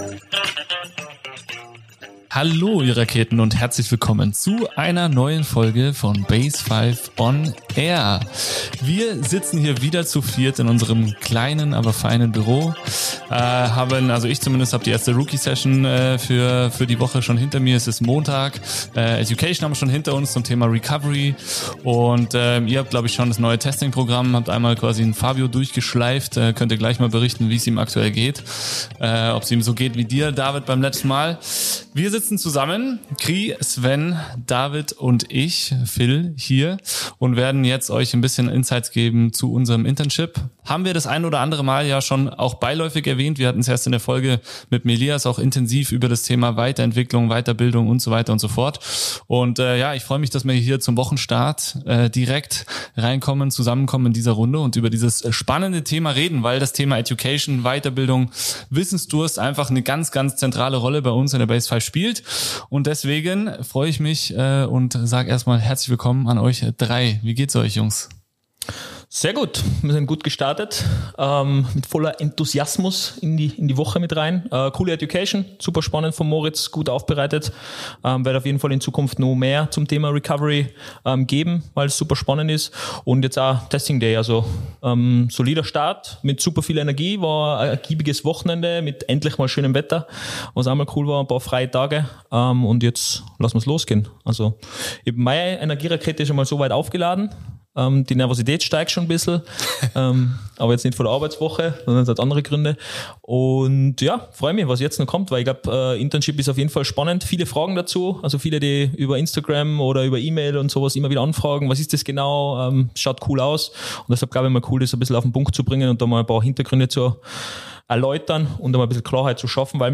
Ein ehrliches Gespräch über persönliche Learnings, neue Wege in der Ausbildung und den Wunsch, junge Berufseinsteiger:innen stärker in der Praxis zu begleiten.